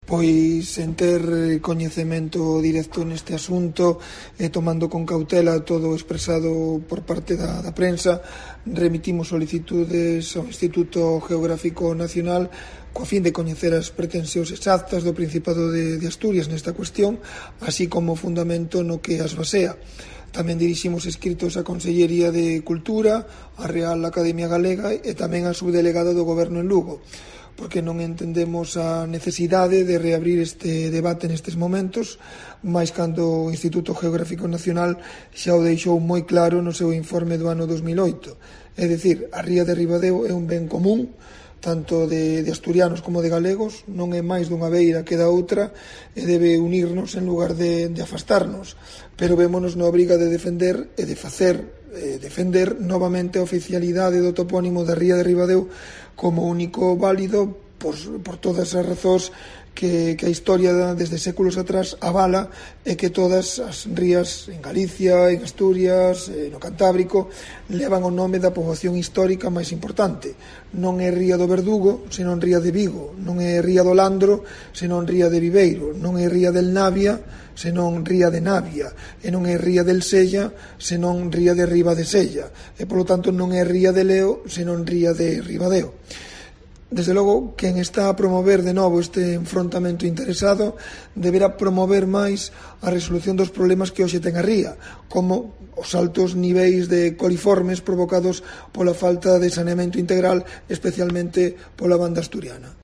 Declaraciones de Fernando Suárez, alcalde de Ribadeo